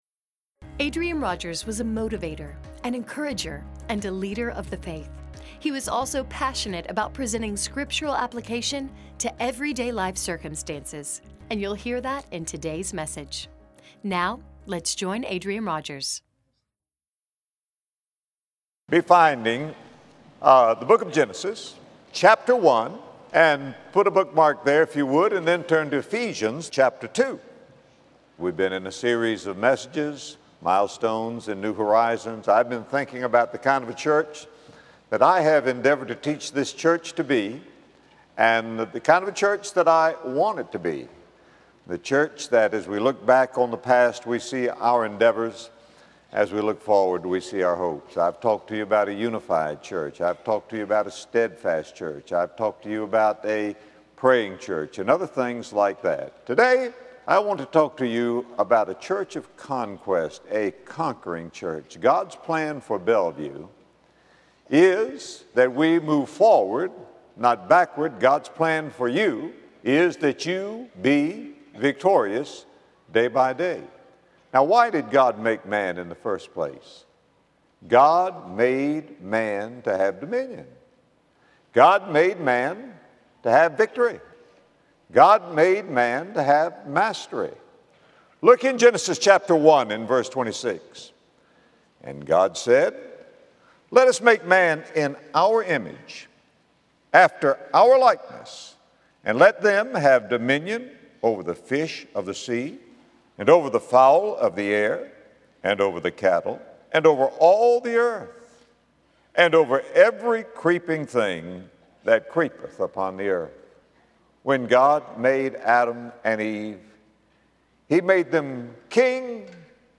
God made man and woman to have dominion, victory and mastery; it is the very reason we were created. In this message, Adrian Rogers shares how Christ has given us kingdom authority on Earth, so we may come together as a conquering church.